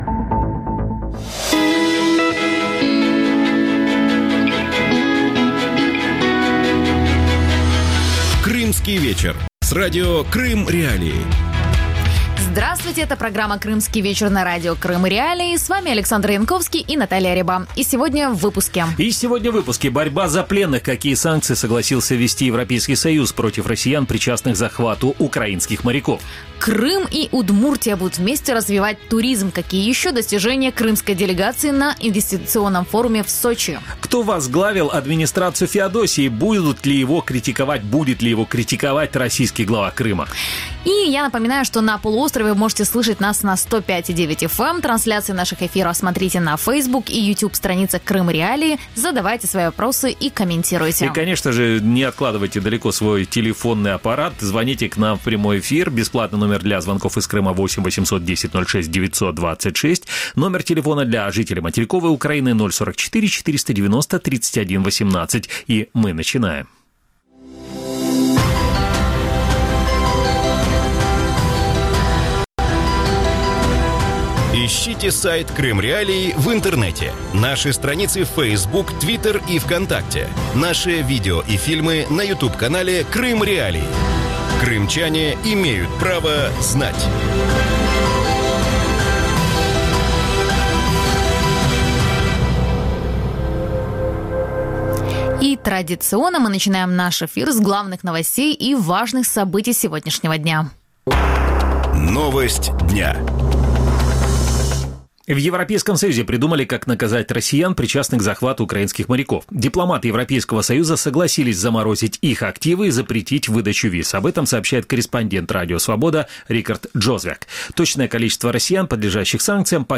Гости эфира
крымский историк и политолог
российский экономист